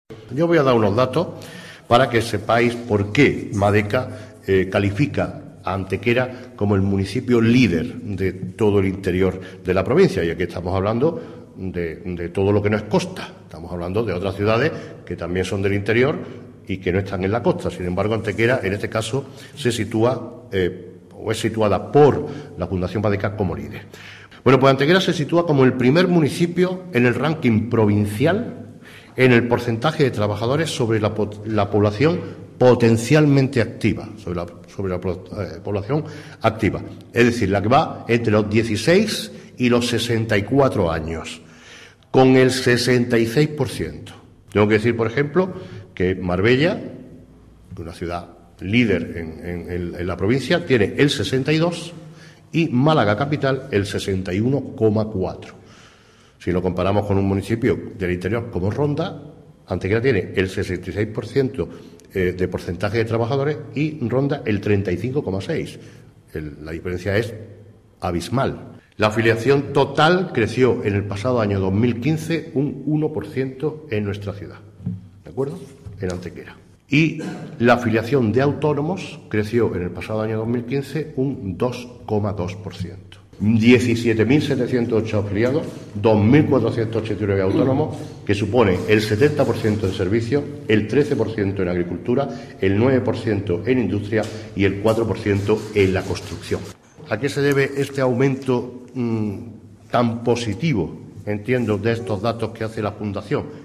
El alcalde de Antequera, Manolo Barón, ha comparecido en la mañana de hoy ante los medios de comunicación para informar sobre los datos más relevantes que se desprenden del informe que la Fundación Málaga Desarrollo y Calidad (MADECA) ha elaborado con fecha de abril del presente 2016 abordando los principales indicativos económicos y laborales de nuestro municipio así como su evolución en los últimos años.
Cortes de voz